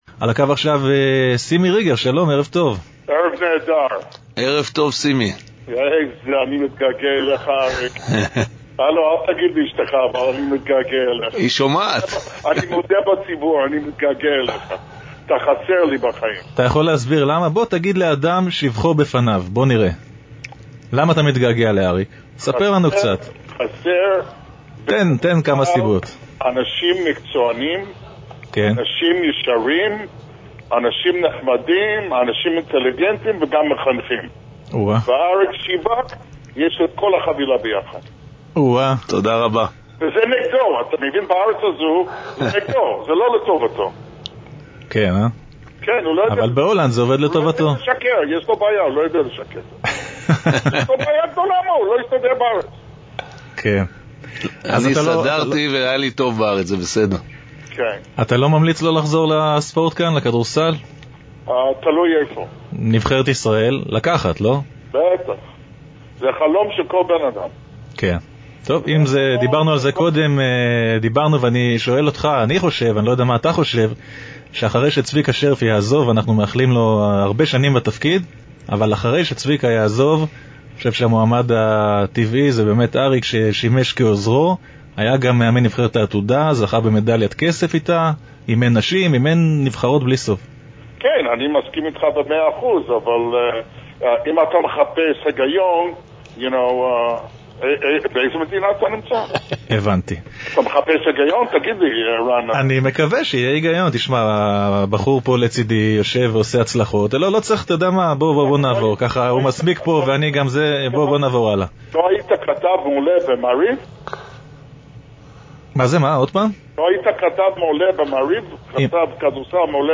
את שאלות המאזינים וקטעי הקישור שבין ראיון לראיון באולפן הורדתי, יען כי רבים הם עד מאוד ועד בלי די ועד אין קץ.